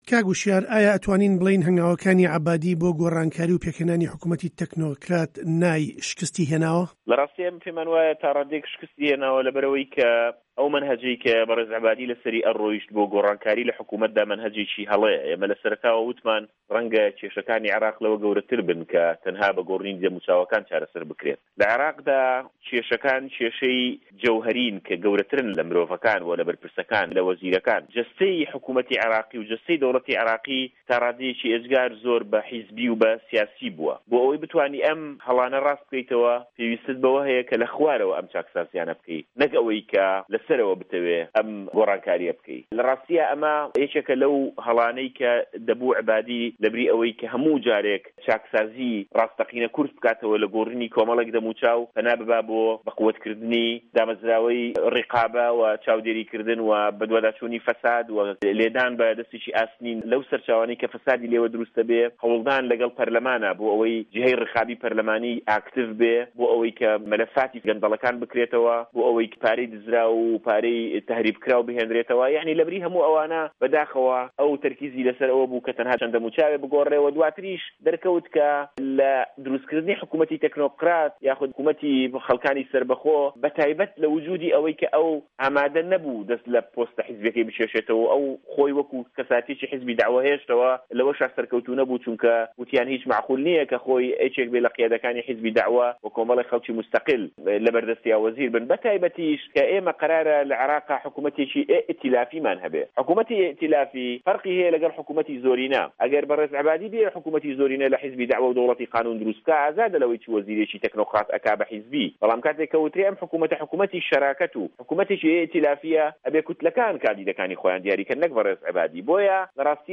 گفتووگۆ له‌گه‌ڵ هوشیار عه‌بدوڵا